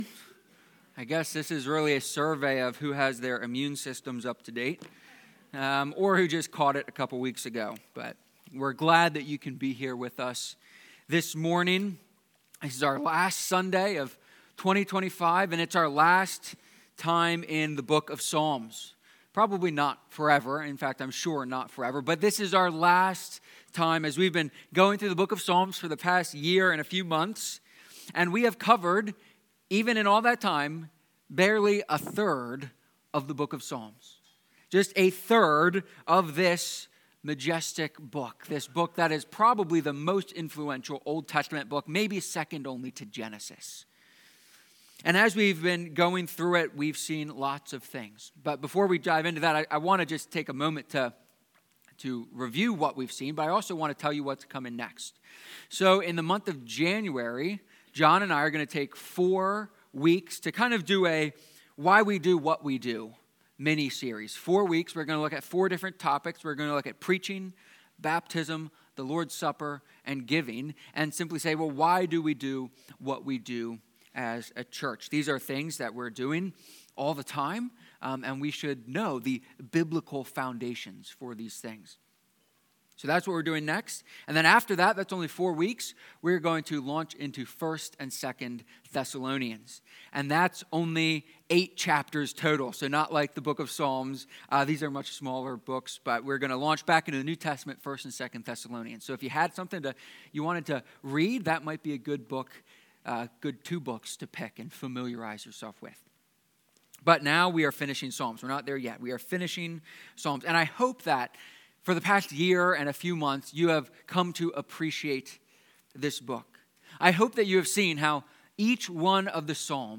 Psalm-150-sermon.mp3